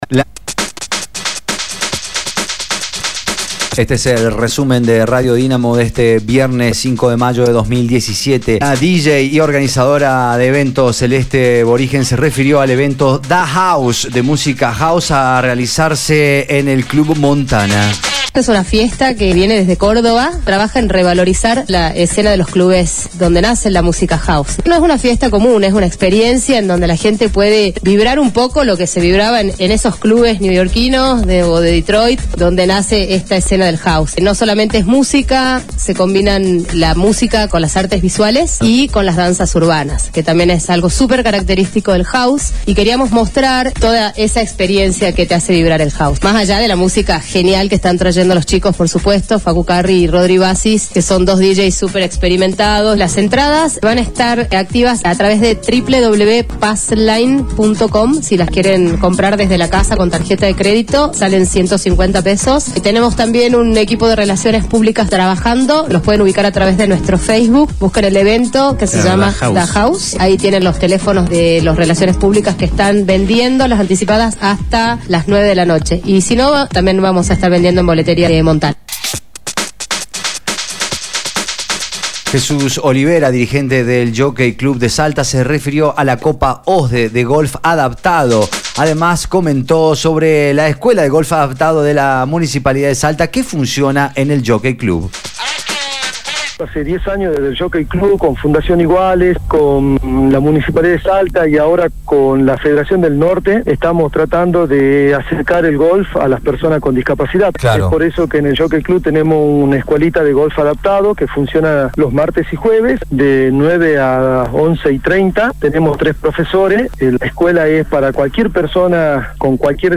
Resumen Informativo de Radio Dinamo del día 05/05/2017 2° Edición